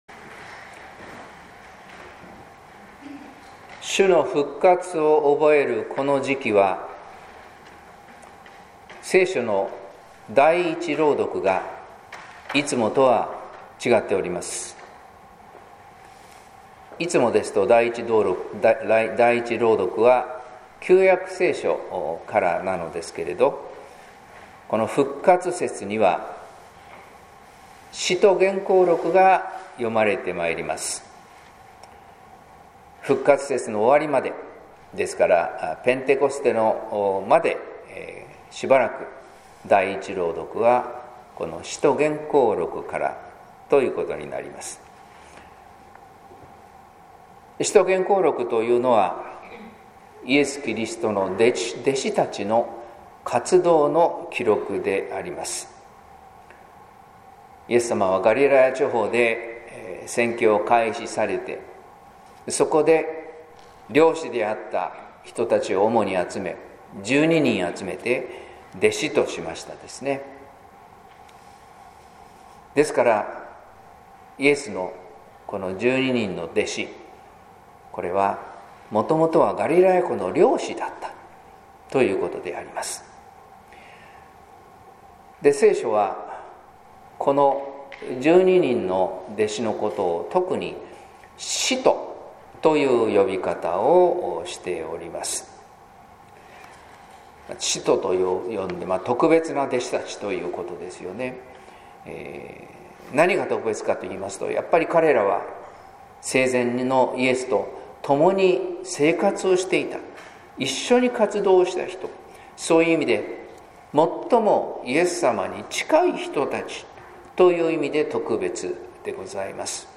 説教「神が選んだ二人」